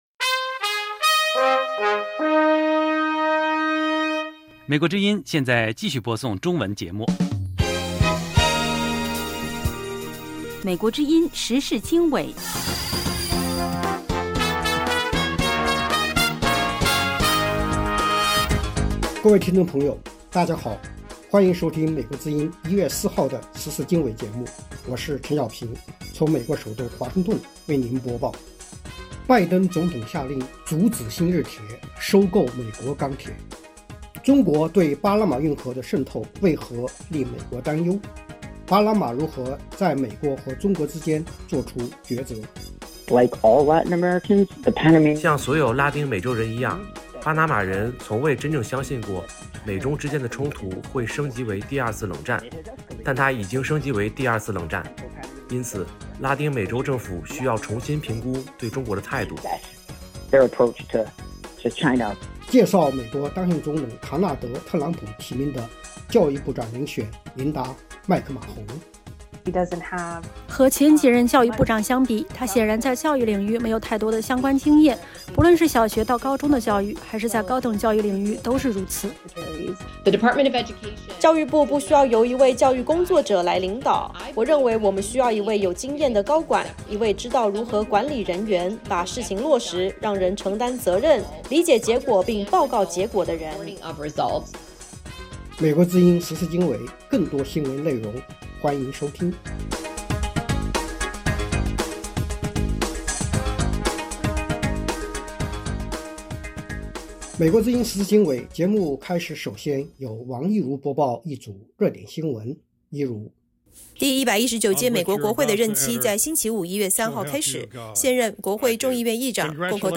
美国之音中文广播《时事经纬》重点报道美国、世界和中国、香港、台湾的新闻大事，内容包括美国之音驻世界各地记者的报道，其中有中文部记者和特约记者的采访报道，背景报道、世界报章杂志文章介绍以及新闻评论等等。